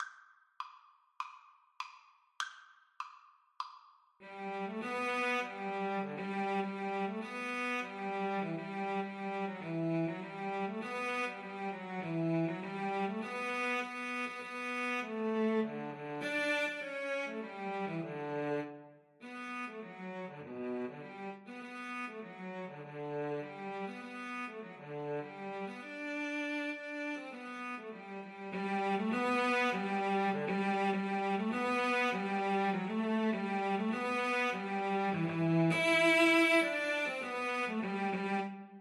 Cello 1Cello 2
Allegro Moderato (View more music marked Allegro)
4/4 (View more 4/4 Music)
Traditional (View more Traditional Cello Duet Music)